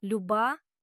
LYOO-bah dear (to a woman)